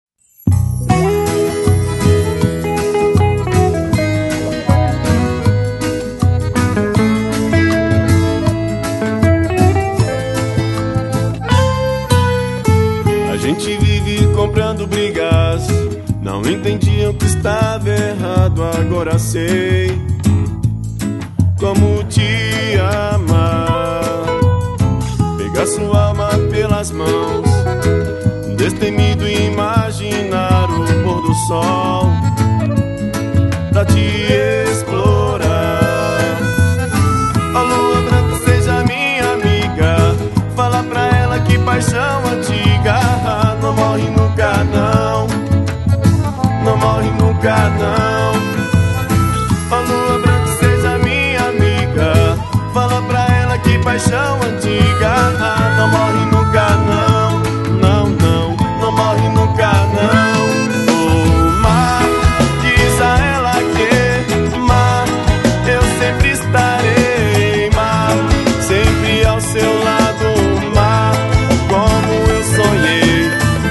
xote